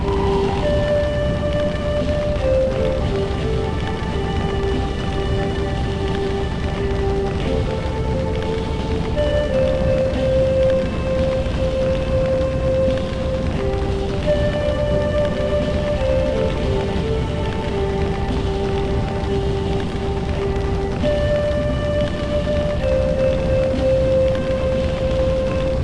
This world's song is a short music loop